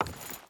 Wood Chain Walk 4.wav